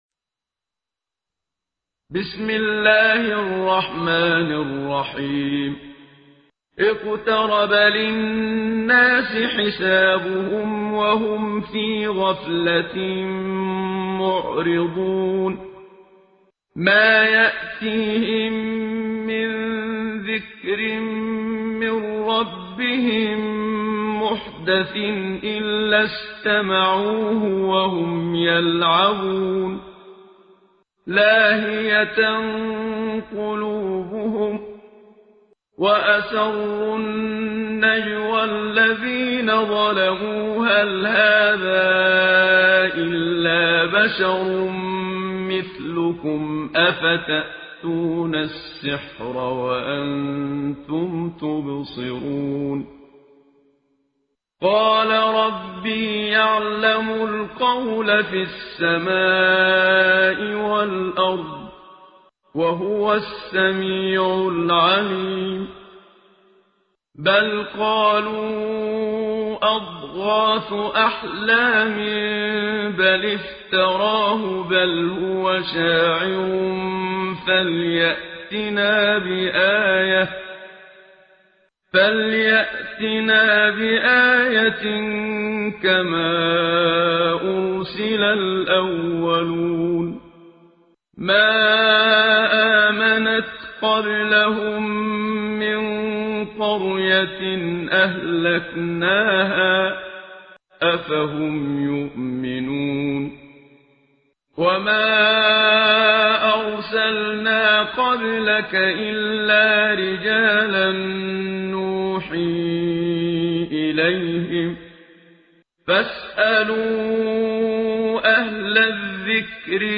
به گزارش نوید شاهد همدان ، ترتیل جزء ۱۷ قرآن کریم با صدای استاد منشاوی به پیشگاه مقدس به ۸۵۳ شهید شهرستان نهاوند تقدیم می‌شود.